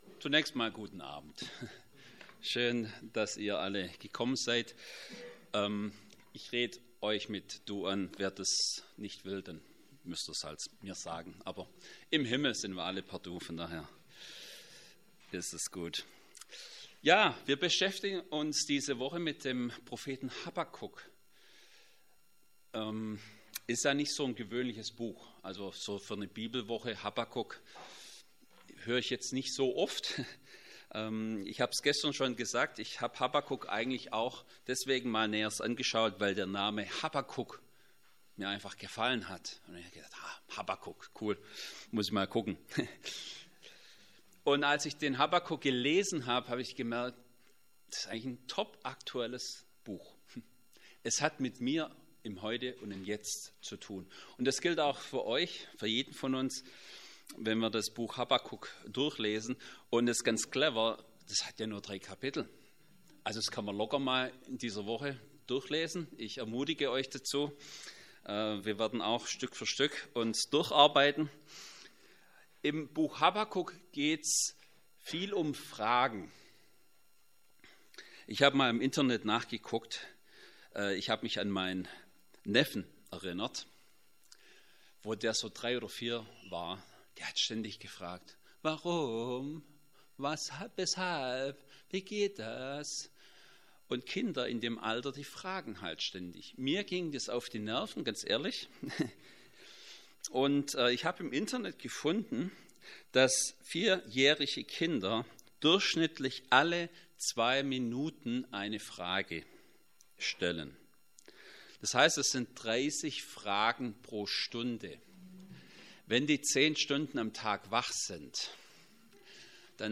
Habakuk 1,1-11 Predigt.mp3